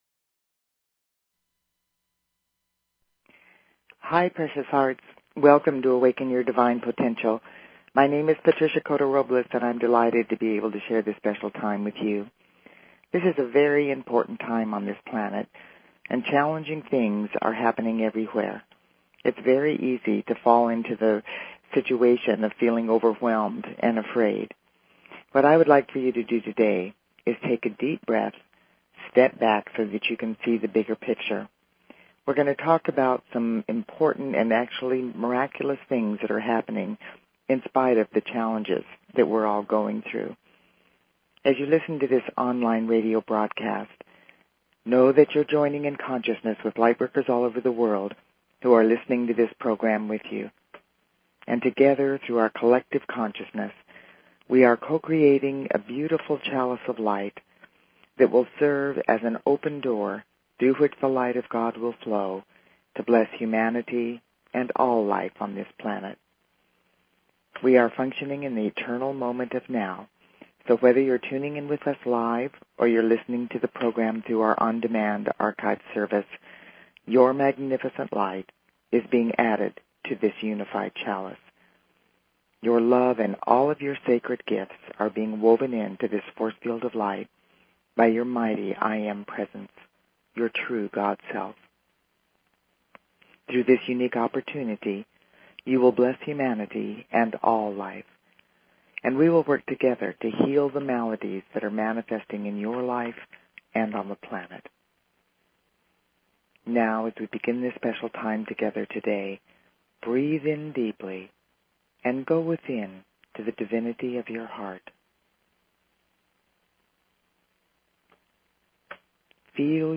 Talk Show Episode, Audio Podcast, Awaken_Your_Divine_Potential and Courtesy of BBS Radio on , show guests , about , categorized as